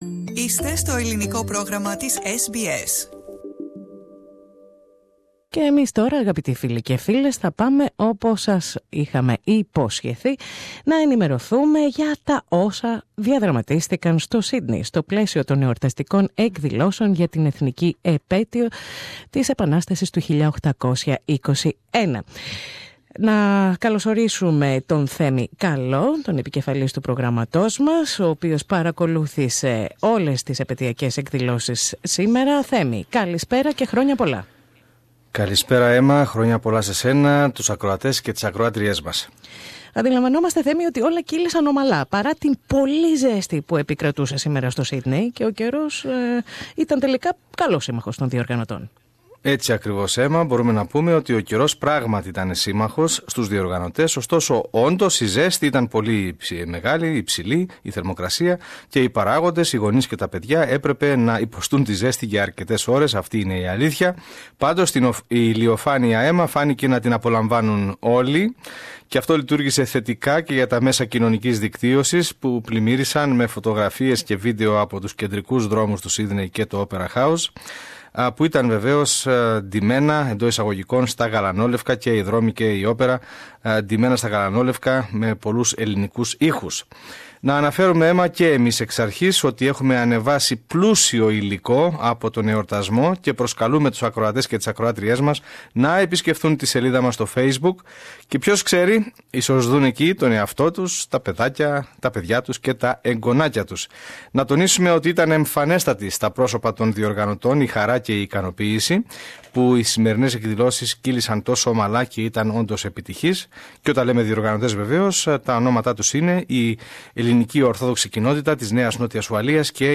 The Greeks of Sydney celebrated the Greek Independence Day with a big-scale parade at Sydney's Harbour.